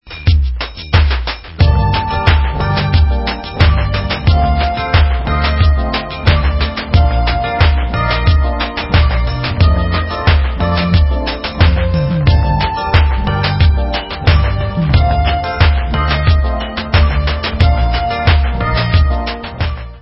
sledovat novinky v oddělení Dance/Electronic